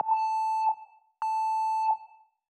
A note is played twice. First time is short slopes from 0 to 127 and back. The second is instantaneous 0 to 127 and slope down to zero. In the first you hear the zipper noise up and down. In the second you hear sharp attack and zipper down.
Audio was exported as a mixdown in NS2.